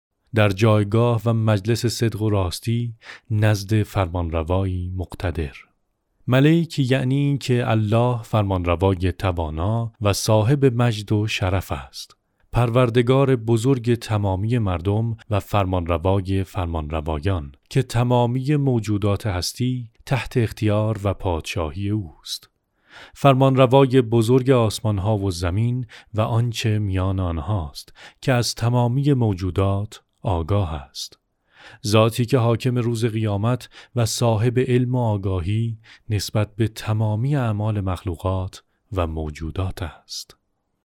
Male
Adult
Islamic-Audio-Book--03